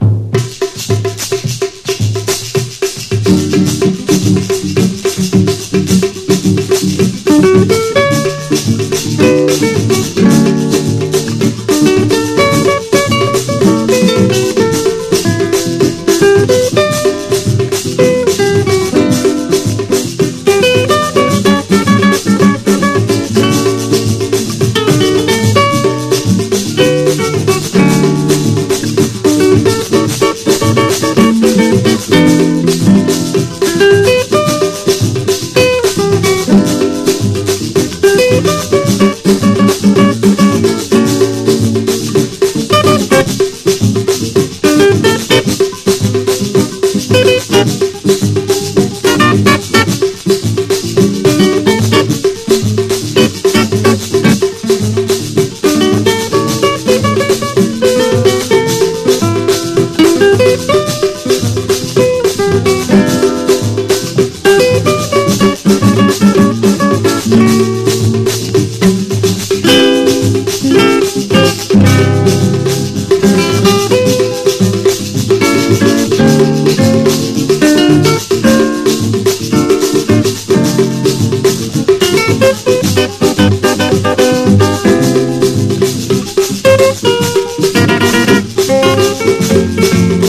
EASY LISTENING / OST / BRAZIL / BOSSA NOVA
パーカッシヴなギターの調べが響く